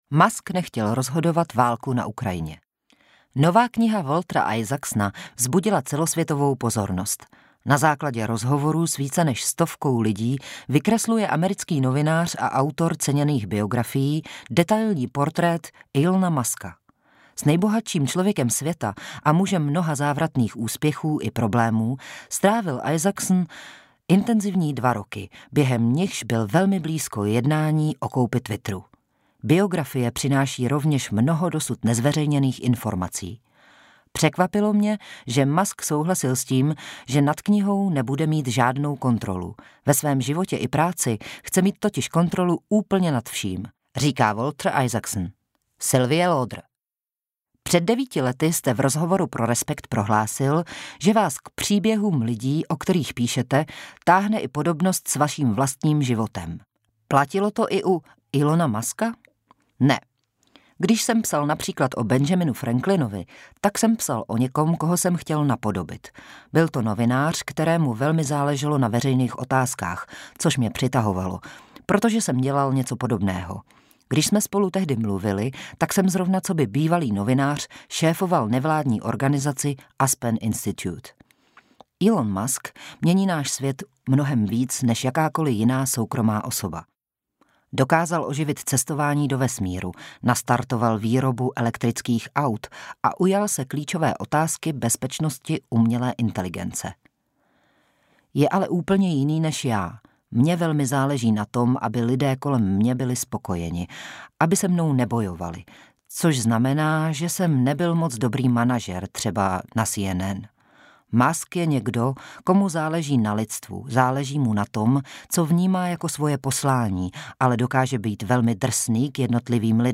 Audioknihy
Minulý týden Nahráno ve společnosti 5Guests.